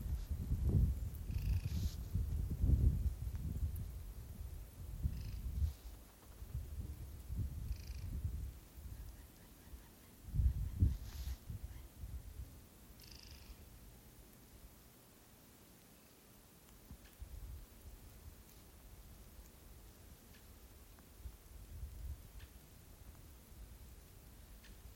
Nutcracker, Nucifraga caryocatactes
StatusVoice, calls heard